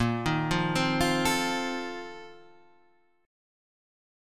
A#M7sus2sus4 chord {6 6 3 5 6 5} chord